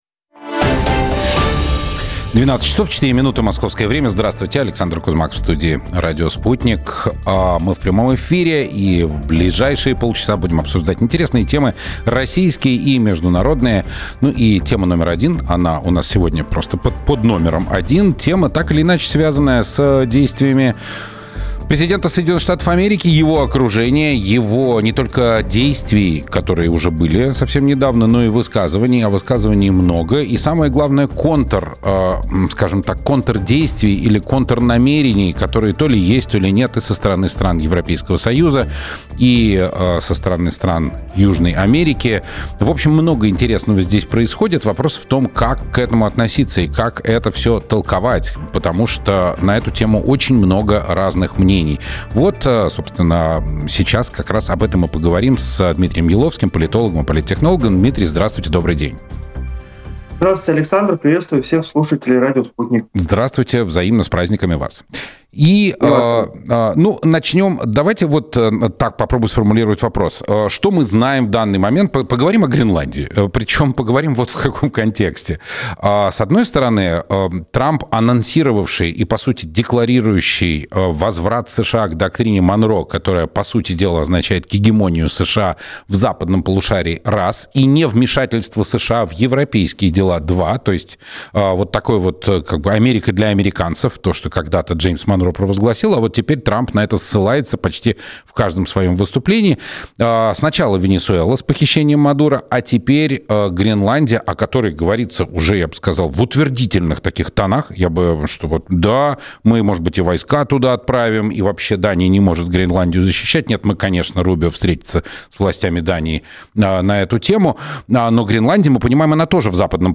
Константин Затулин на Радио Спутник. Эфир от 8 января 2026 г. - Константин Затулин - официальный сайт
Гости Радио Спутник 8 января